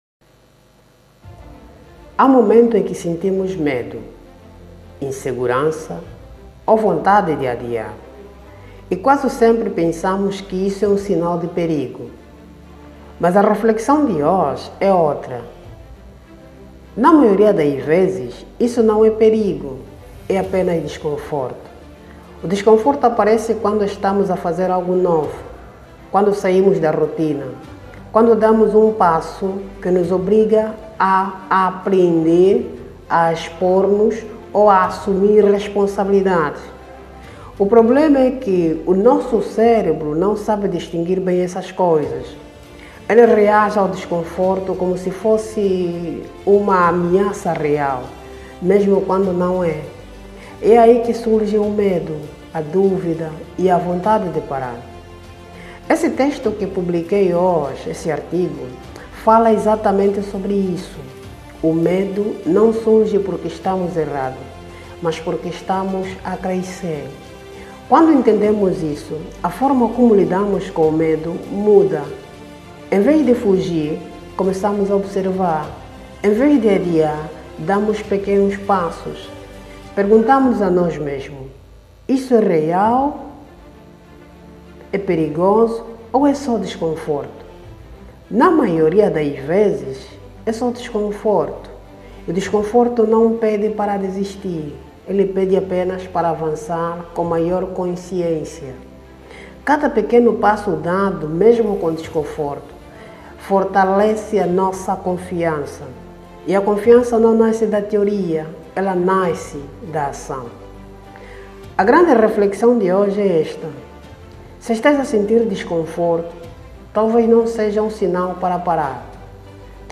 🎧 Resumo em Áudio – O medo e o próximo nível no negócio online